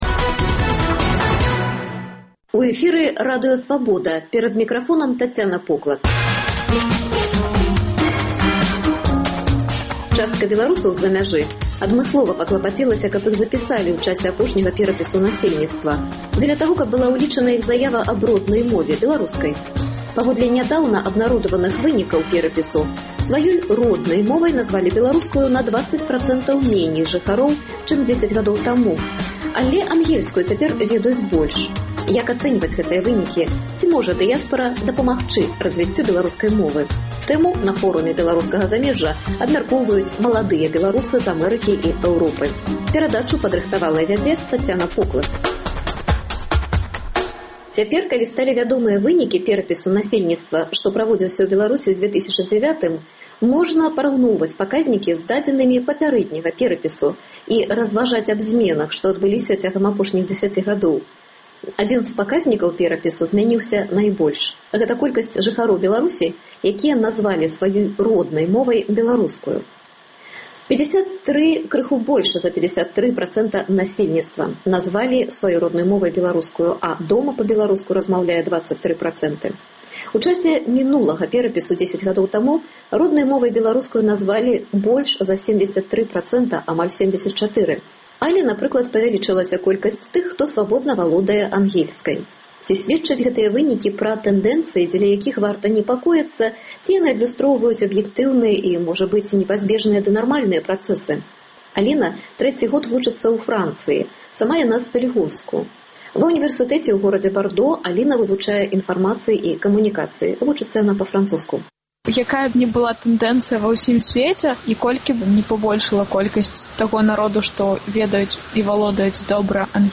Як ацэньваць гэтыя вынікі і ці можа дыяспара дапамагчы разьвіцьцю беларускай мовы? Гэтую тэму на Форуме “Беларускага замежжа” абмяркоўваюць маладыя беларусы з Aмэрыкі і Эўропы.